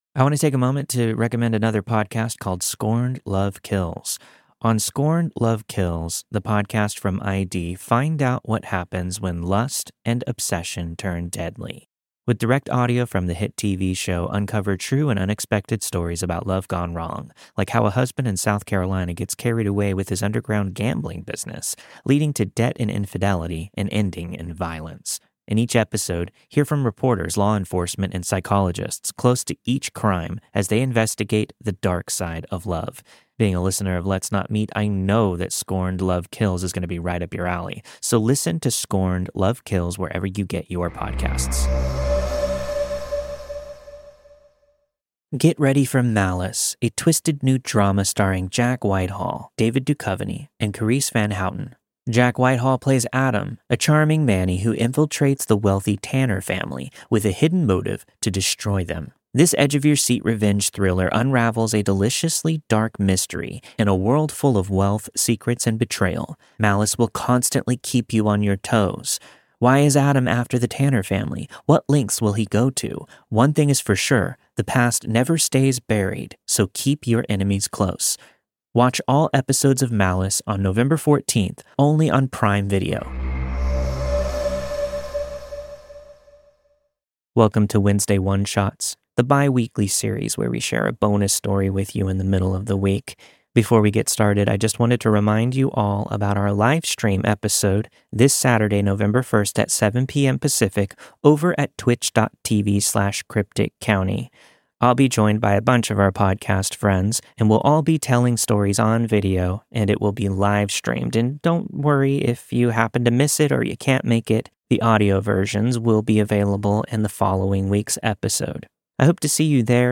The story you've heard this week was narrated and produced with the permission of the respective author.